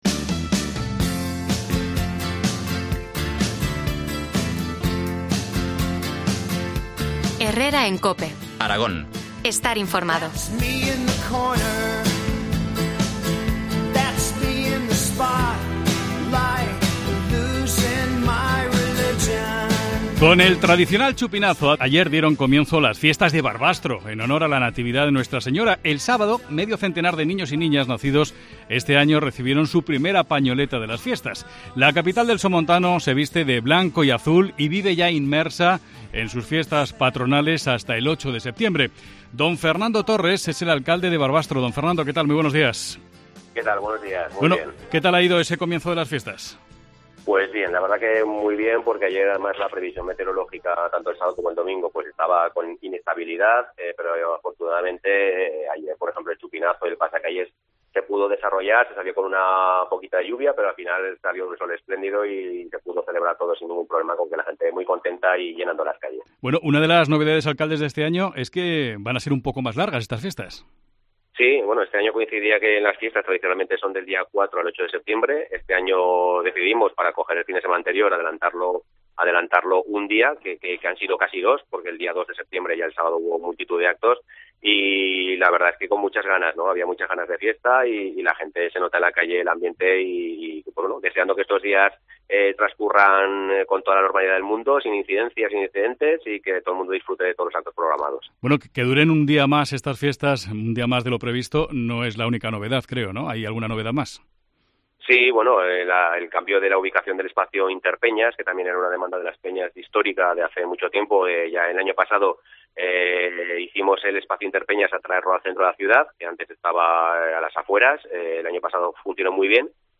Entrevista al alcalde de Barbastro, Fernando Torres.